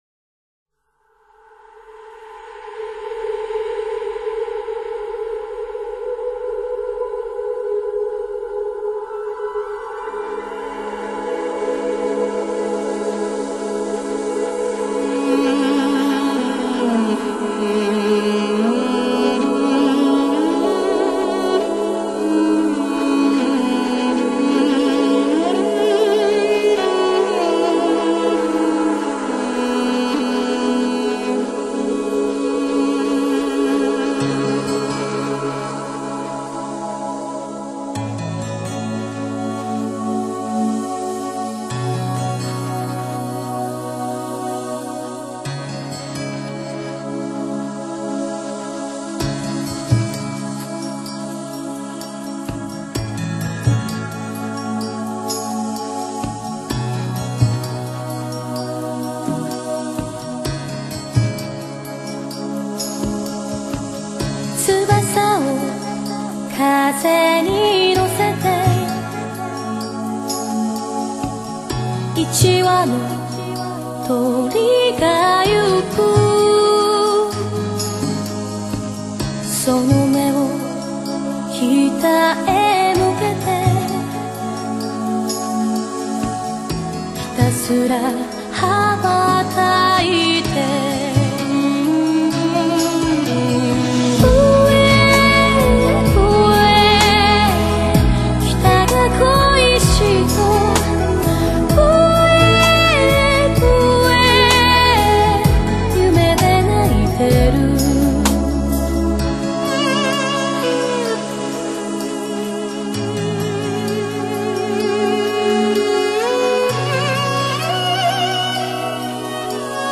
她清透的噪音，溫柔中又透露着一丝的坚强
加了马头琴前奏，悠扬而凄凉的马头琴声，使人听着感觉是那么的忧伤与无奈